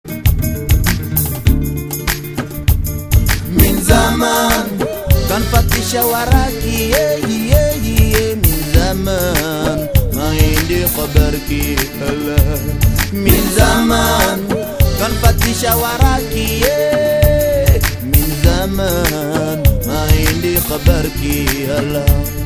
Hip Hop Gruppe
Aber vom style hätt ich eher auf arabische musik getippt.